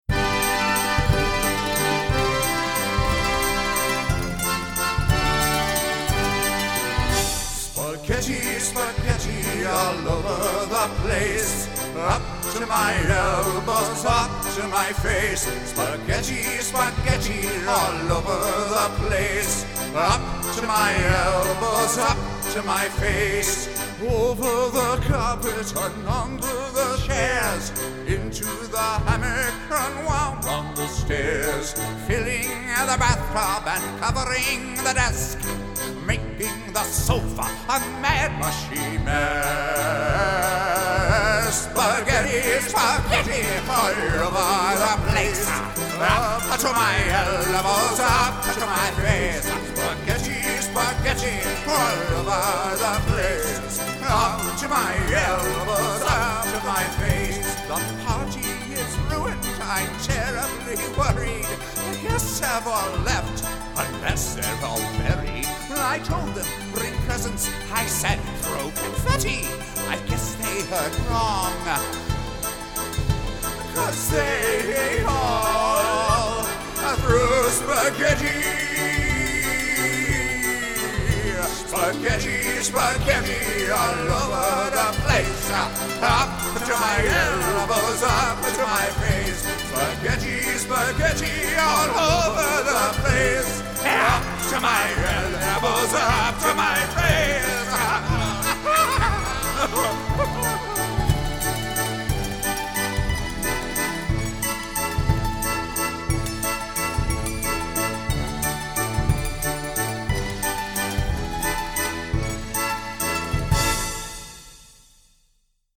From there, he and I have used all of the fun tools of the electronic age to make those songs danceable, singable, and generally fun for the kids and their parents to learn.
spaghetti_w_vocal.mp3